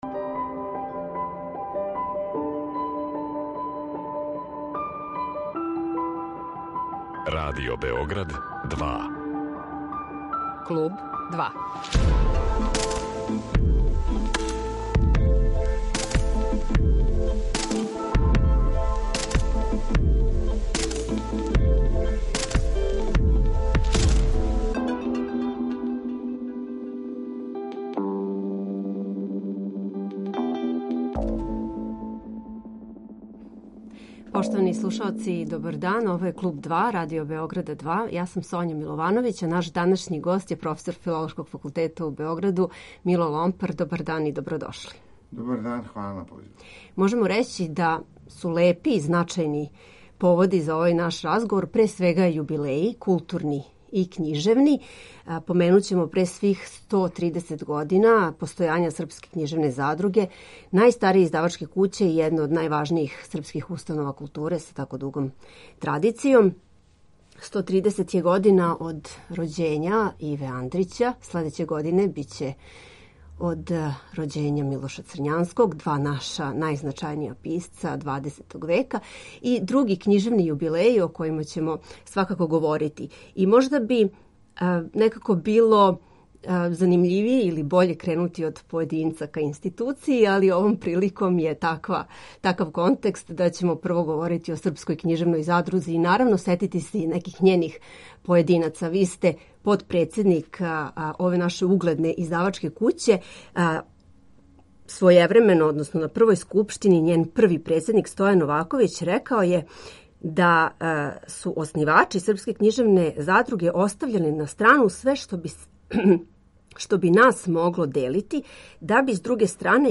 Гост Клуба 2 је професор Филолошког факултета у Београду Мило Ломпар.